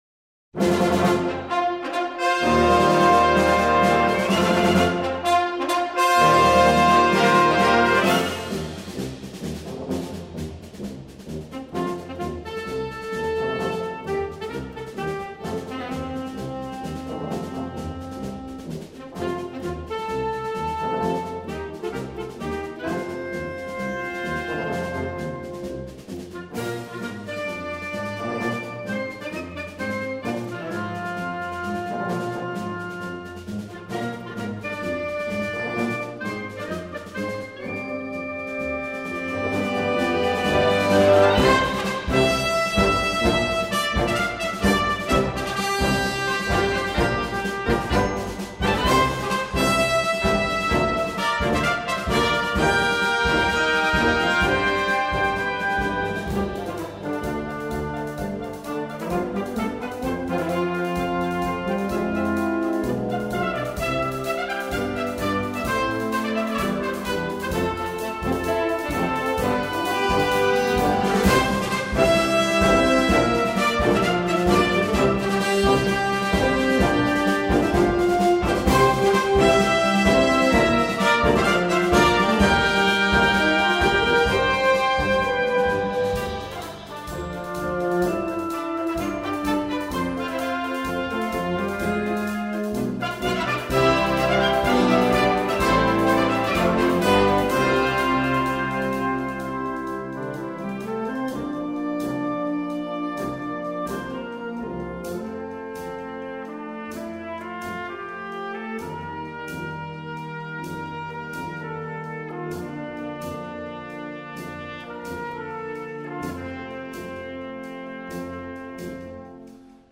march
Wind Orchestra Grade 3-5